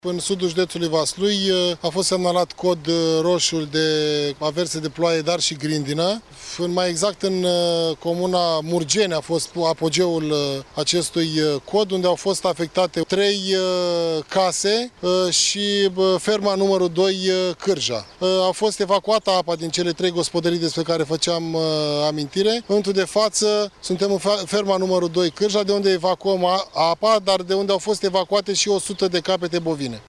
Inspectoratul Județean pentru Situații de Urgență Vaslui a intervenit la scoaterea apei din gospodăriile inundate, a declarat prefectul de Vaslui Eduard Popica.